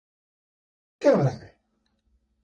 Il est quelle heure Hyphenated as che‧ó‧ra‧è Pronounced as (IPA) /ke ˈo.ra ˈɛ/ Show popularity over time Bookmark this Improve your pronunciation Notes Sign in to write sticky notes